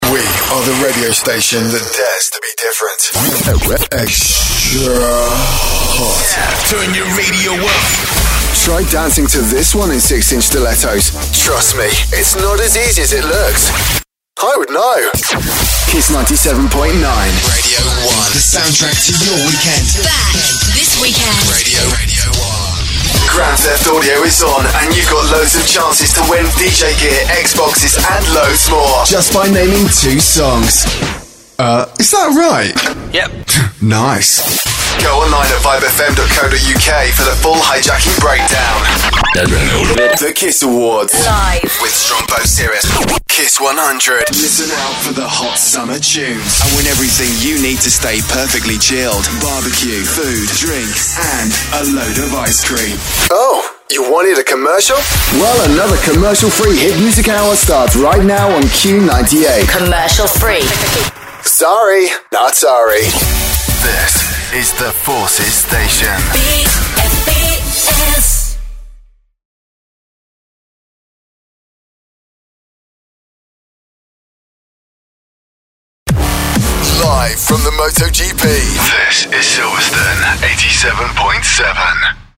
Imagens de rádio
Meu tom amigável e pé no chão, que cai na faixa baixa a média, conecta-se efetivamente com o público de uma maneira coloquial que é envolvente e acessível.
Microfone Neumann TLM193